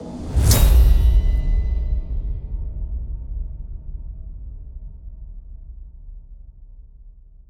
impact.wav